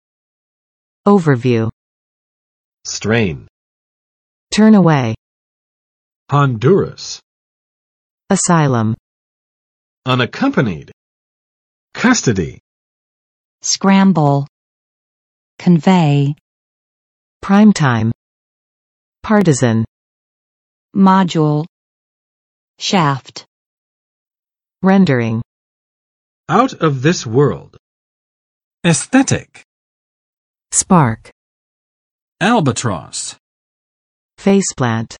[ˋovɚ͵vju] n.【美】概观；概要；综述